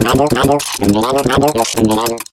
Media:tick_vo_06v2.ogg Tick blathers.
迪克絮絮叨叨